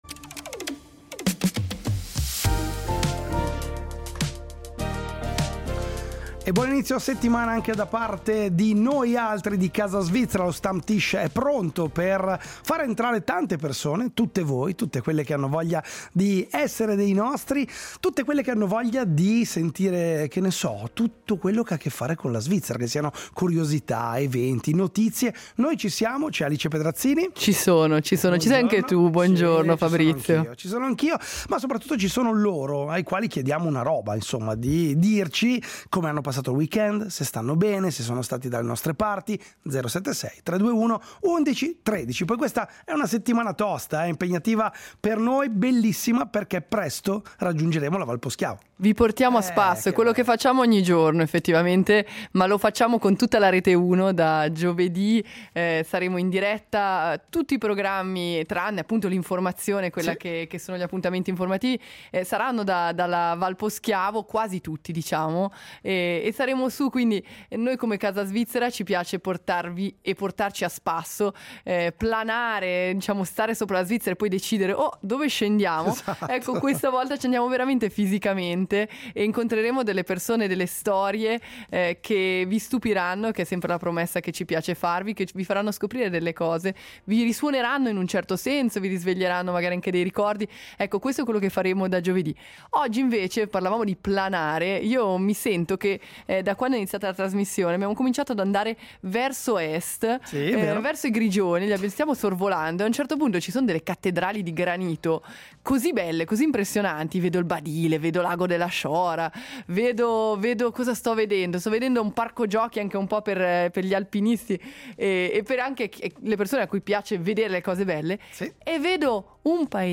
E abbiamo riascoltato anche la sua voce d’archivio, quando si definiva – con sorprendente umiltà – un «artista mancato».